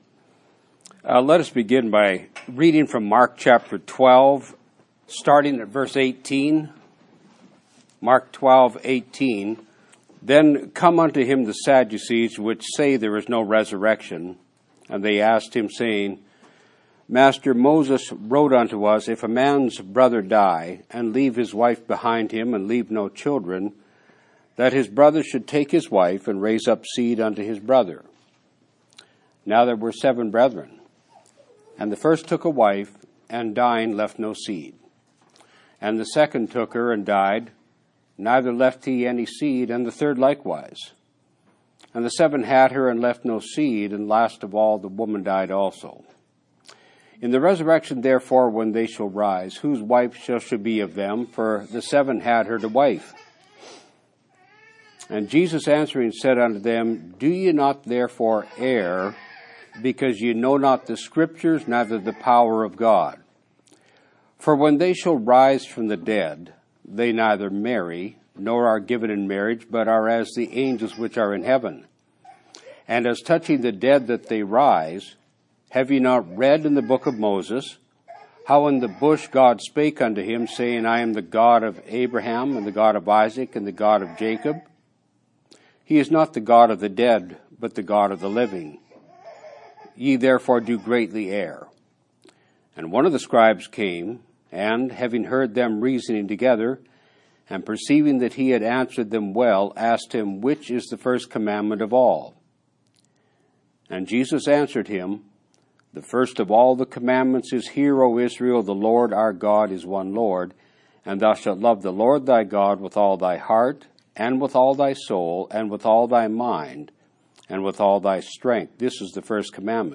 Sermons by Series: 'Bringing Thoughts Into Captivity' | Cincinnati Church